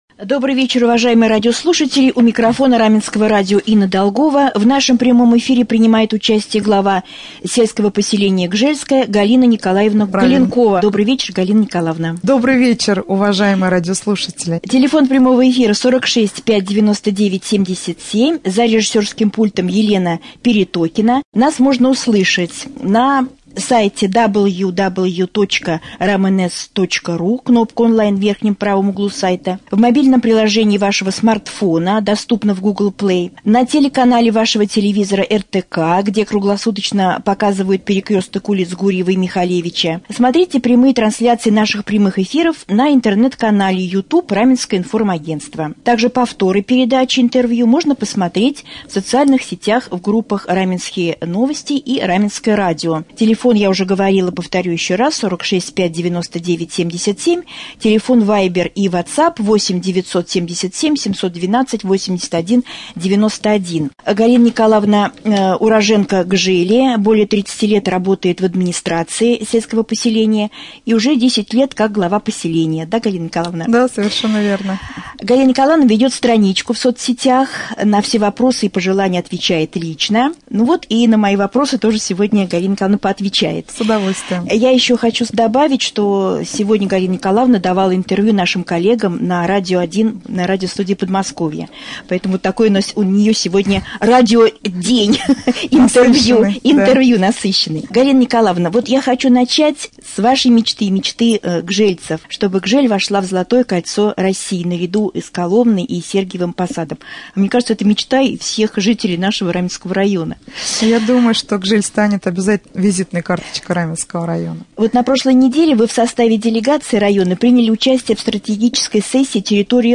Pryamoj-efir-1.mp3